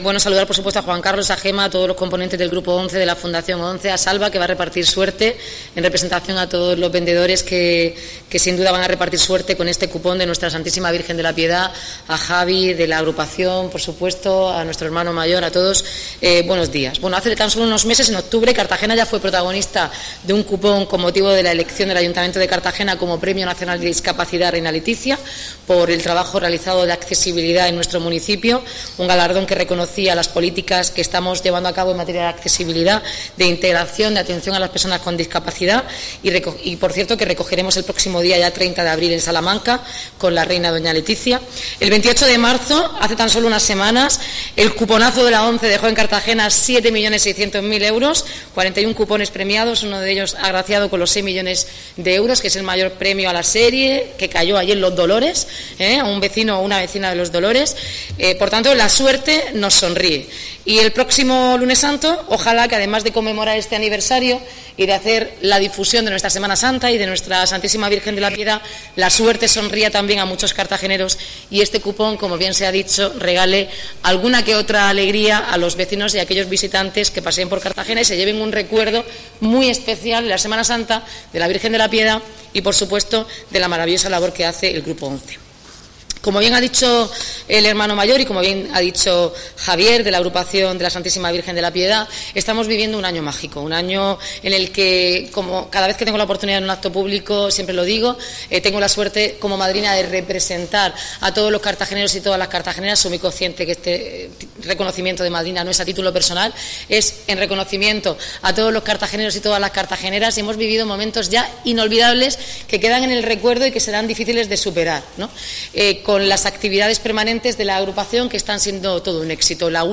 Enlace a Declaraciones de la alcaldesa, Noelia Arroyo, sobre Cupón de la ONCE dedicado a La Piedad